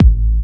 Kick OS 08.wav